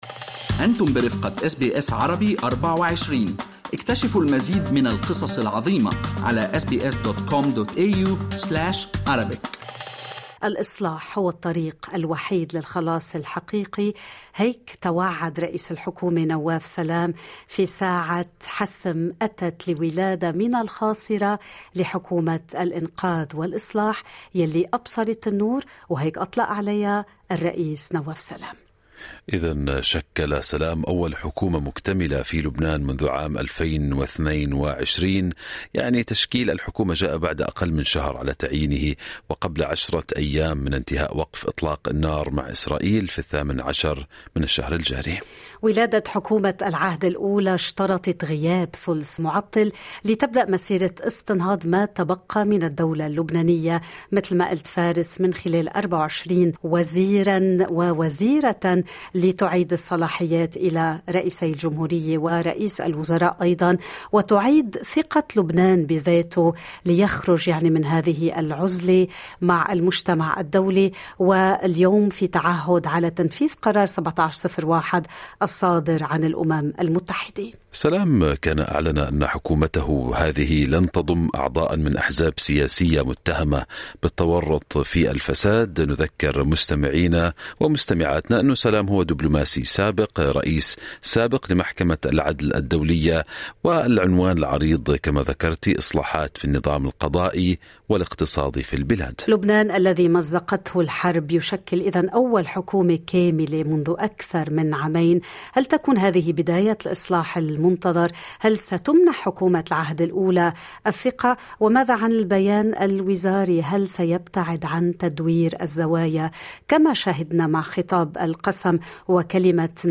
الإجابة مع الباحث والأكاديمي